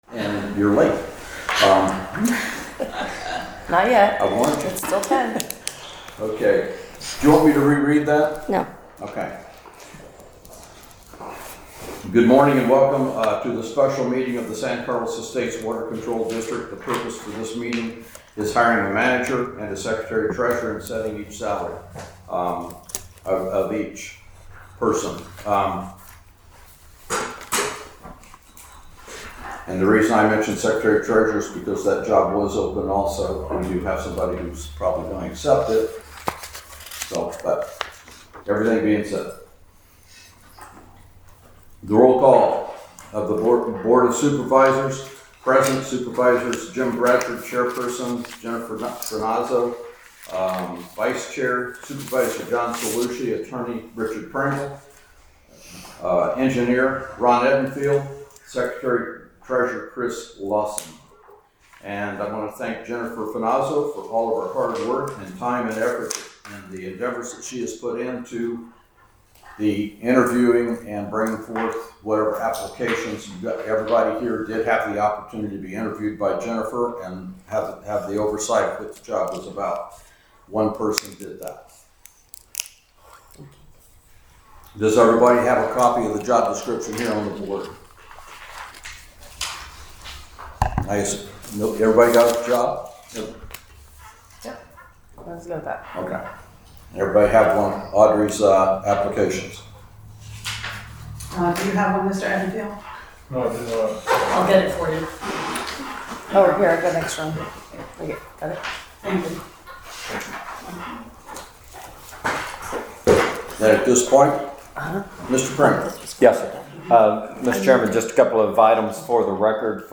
Board Meetings: Gulf Shore Church, 25300 Bernwood Dr, Bonita Springs FL 34135 Monthly Meeting